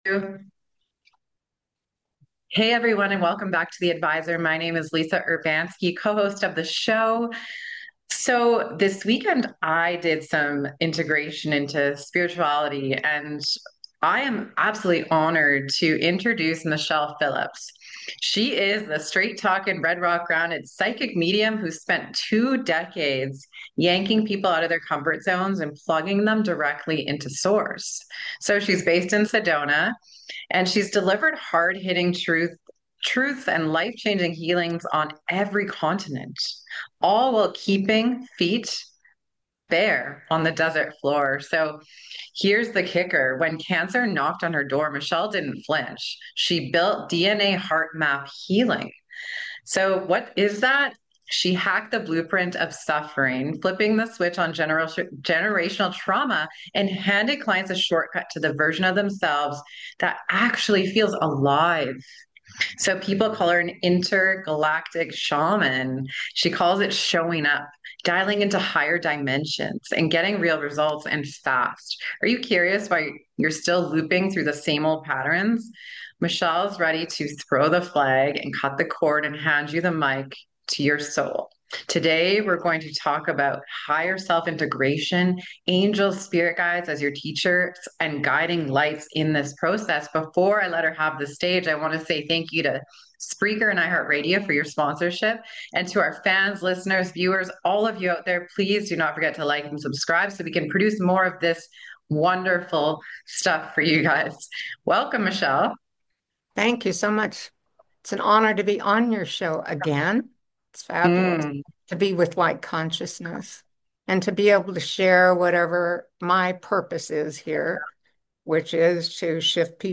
Whether you’re curious about mediumship, craving deeper self-awareness, or seeking practical tools to raise your vibration, this enlightening conversation delivers action-packed tips and heartfelt inspiration.